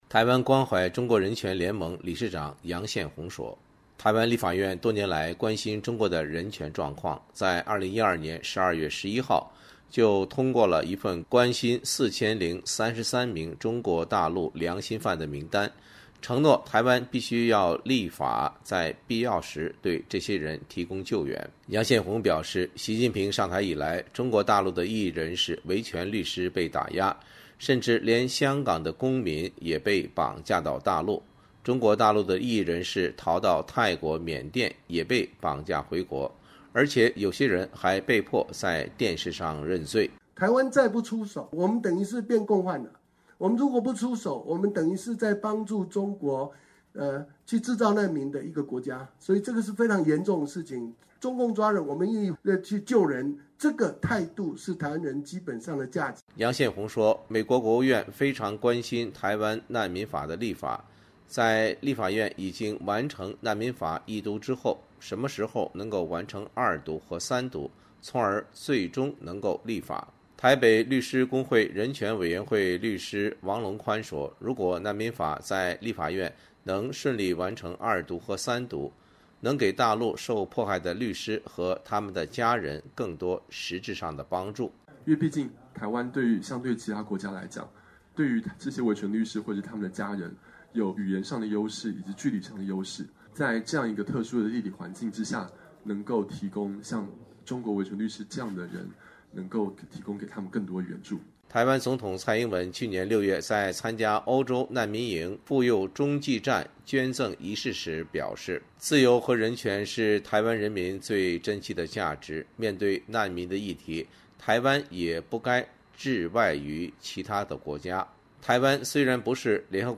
台湾多个人权团体记者会现场